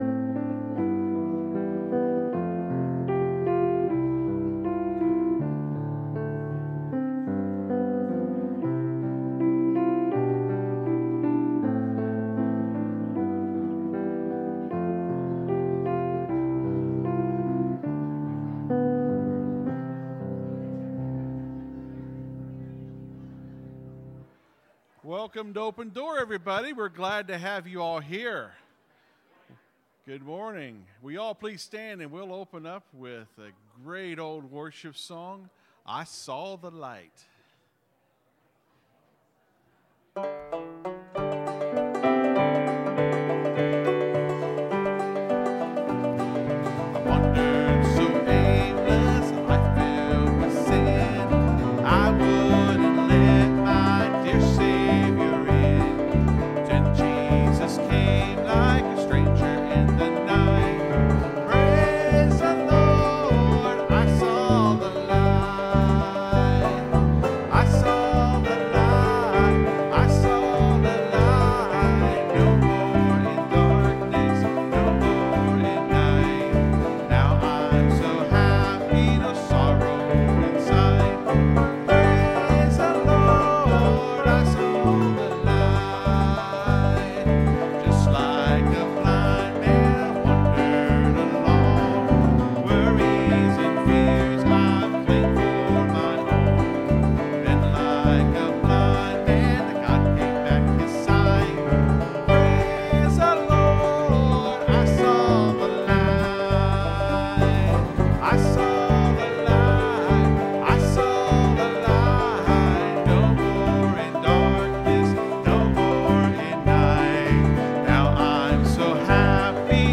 (Sermons starts at 24:00 in the recording).